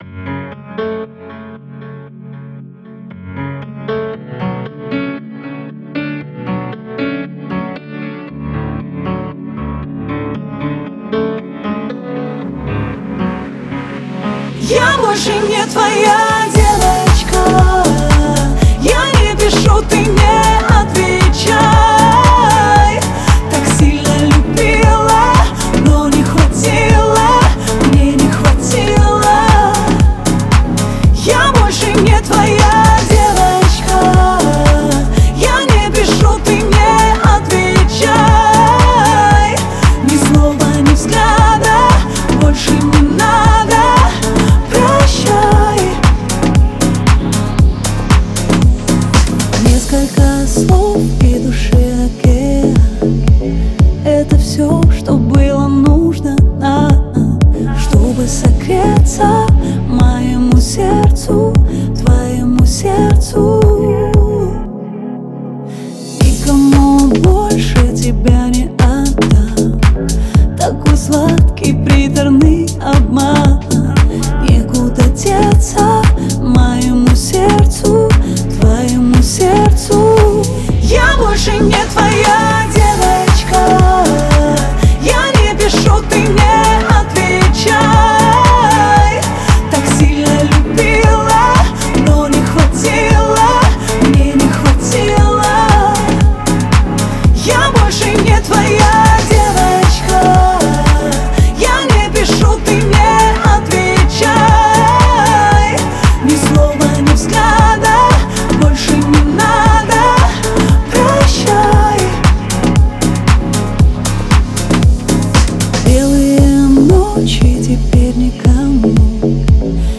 яркая поп-песня